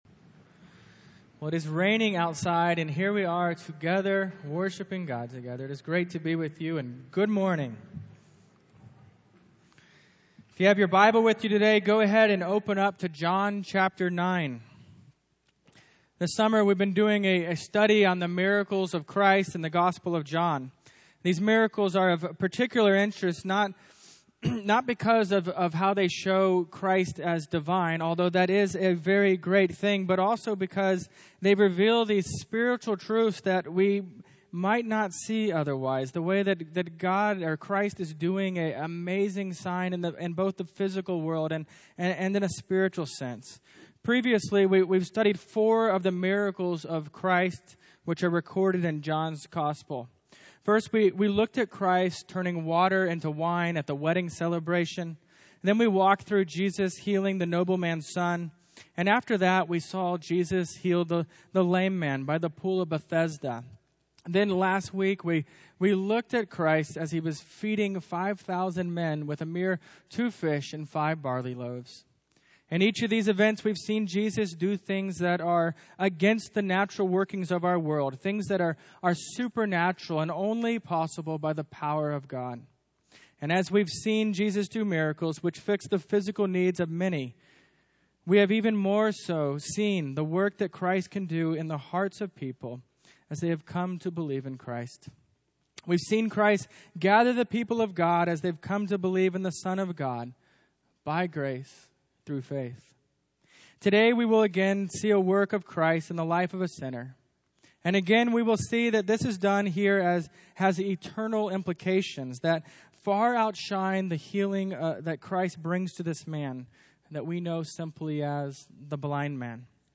Series: Miracles of Jesus in the Gospel of John Passage: John 9:1-41 Service Type: Morning Worship « Jesus did What?